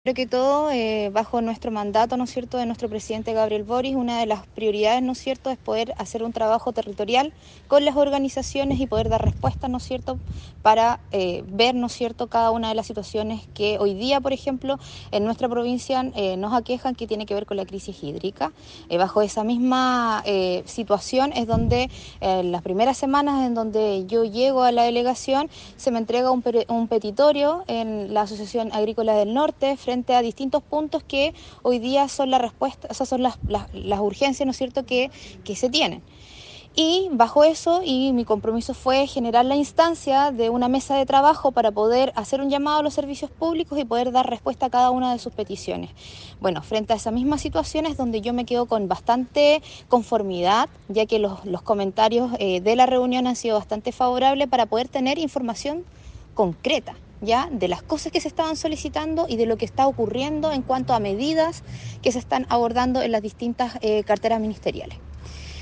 La Delegada Presidencial Provincial de Limarí, Marily Escobar Oviedo, indicó sobre esta reunión que
MARILY-ESCOBAR-OVIEDO-DPP-LIMARI.mp3